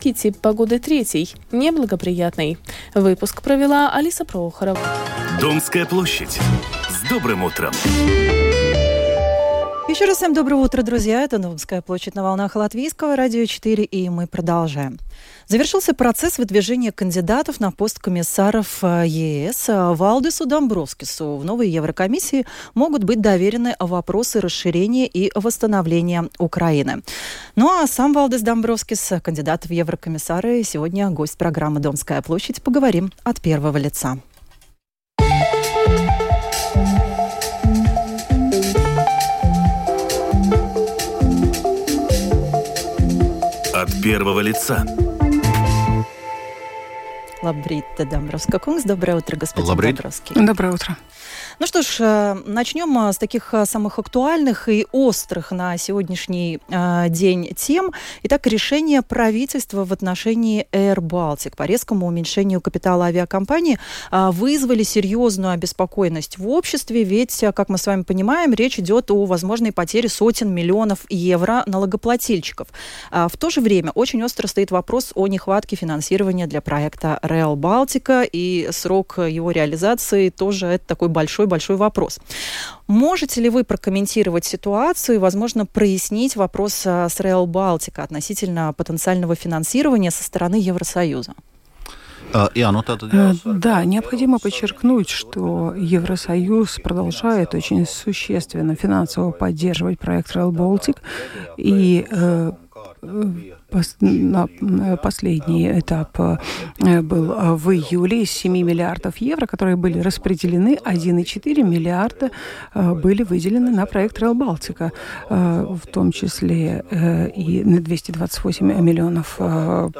Будет ли Евросоюз и дальше выделять Латвии деньги на этот проект? Обо всем этом этом в программе “Домская площадь” рассказал кандидат в Еврокомиссары, нынешний заместитель председателя Еврокомиссии Валдис Домбровскис.